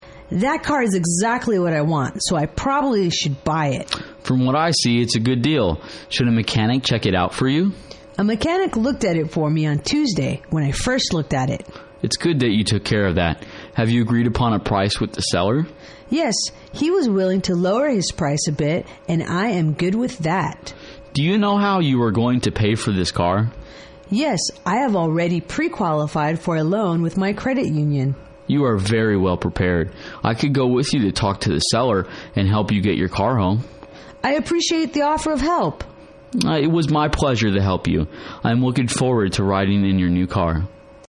英语情景对话-Deciding to Buy the Car(2) 听力文件下载—在线英语听力室